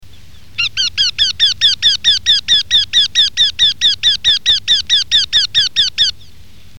Torcol fourmilier
Jynx torquilla